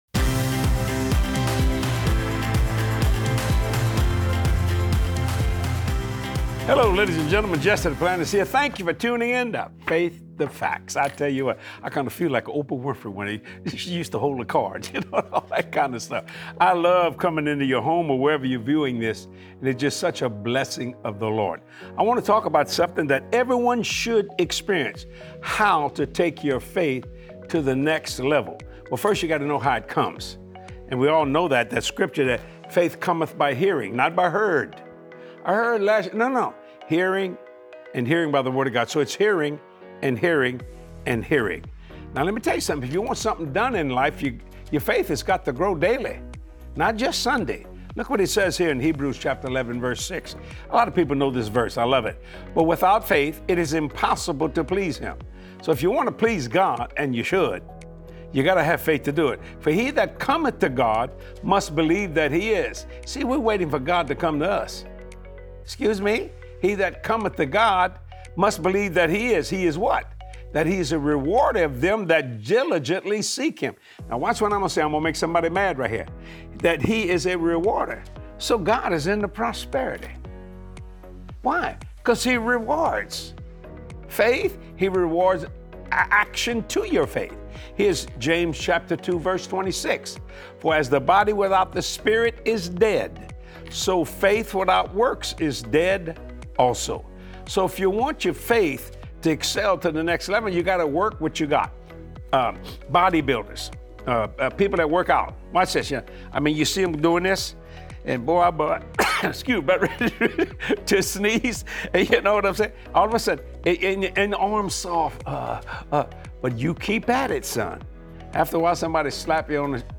Are you ready to elevate your FAITH more than ever before? Be inspired to live higher in Christ as you watch this empowering teaching with Jesse Duplantis.